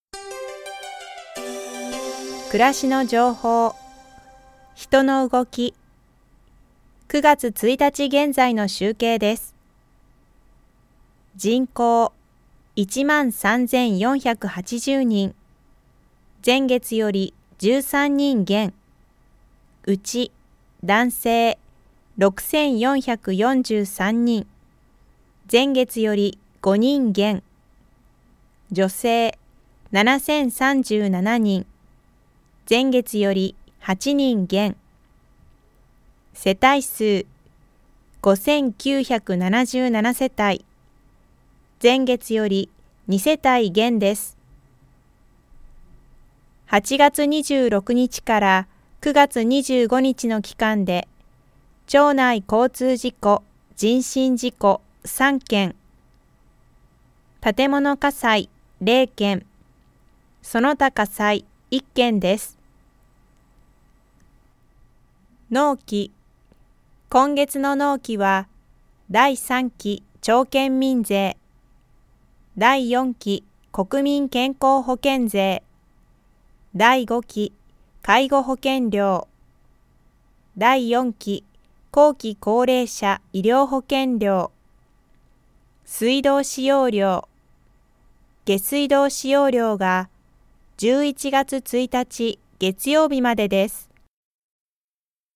声の広報
広報誌の一部を読み上げています。